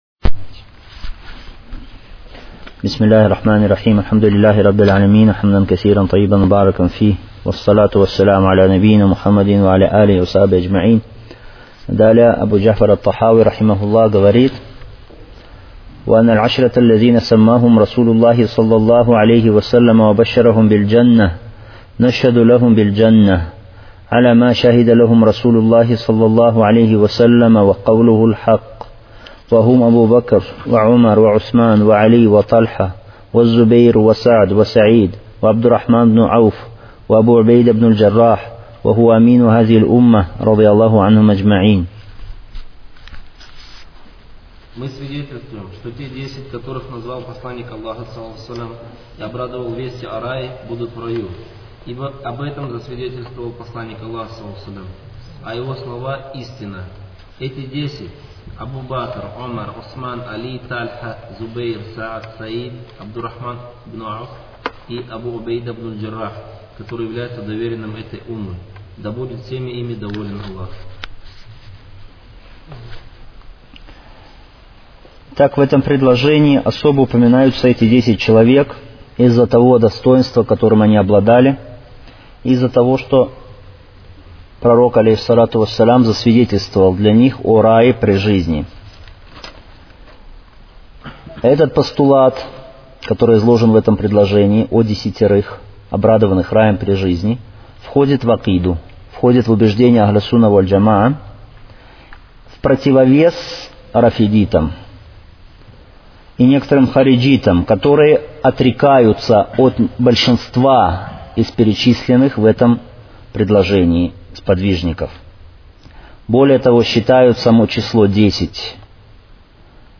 Серия уроков в пояснении книги «Акида Тахавия».